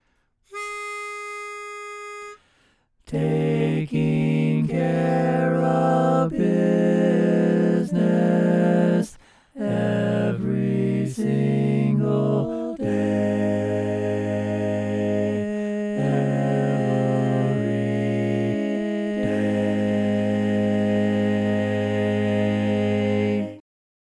Key written in: A♭ Major
Type: Barbershop
Learning tracks sung by